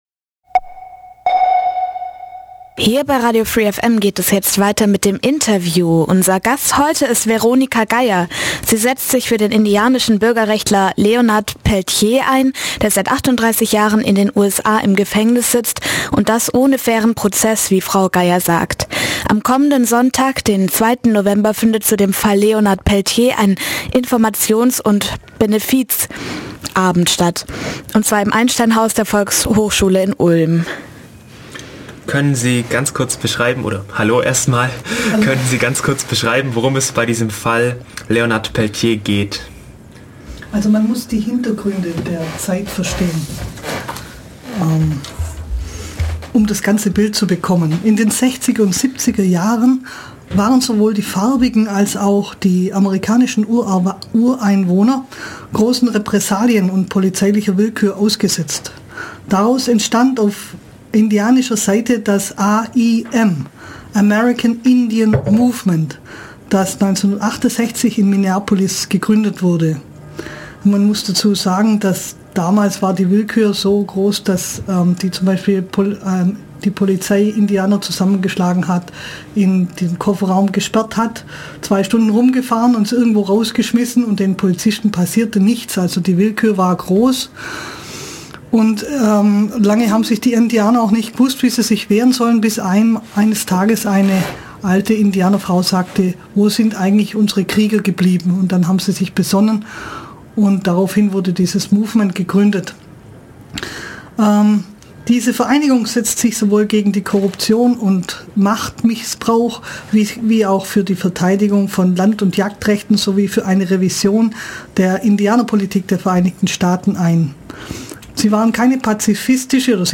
interview_leonardpeltier.mp3